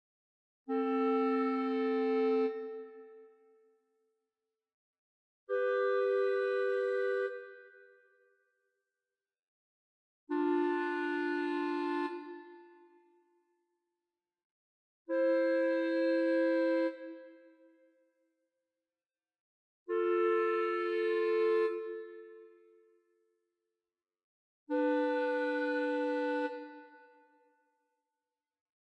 L'ascolto propone sei intervalli: classificali.
intervalli.mp3